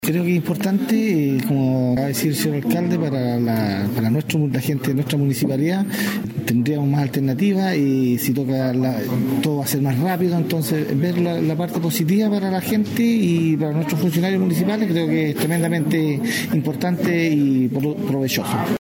El concejal Rubén Ortiz también valoró la iniciativa, resaltando que permitirá servicios más rápidos y accesibles para quienes trabajan en la municipalidad.